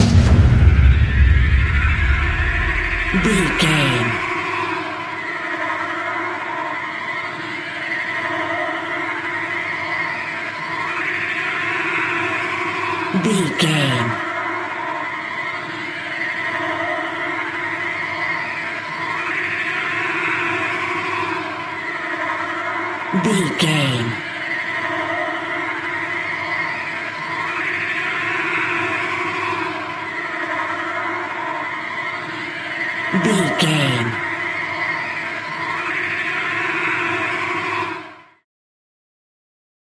Horror String Hit.
In-crescendo
Atonal
ominous
eerie
strings
percussion